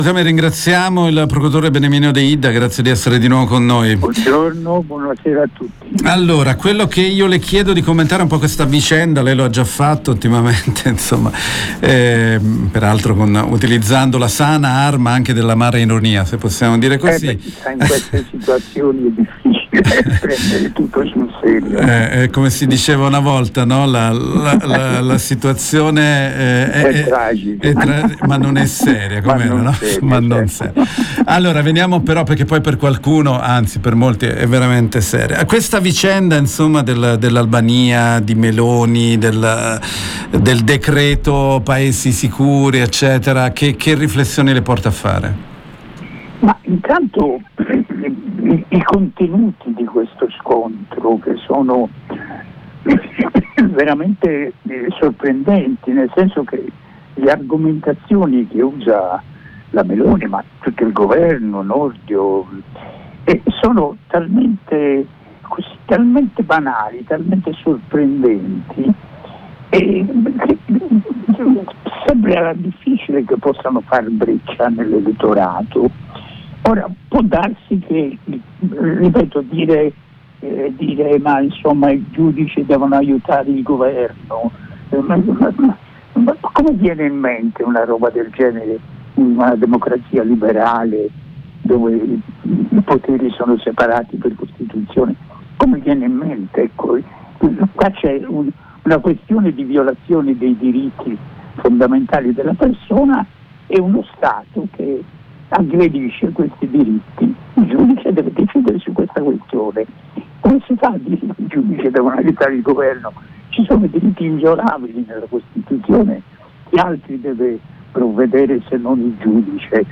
Migranti minoriIl giorno dopo  il varo del decreto Paesi Sicuri abbiamo  intervistato il procuratore Beniamino Deidda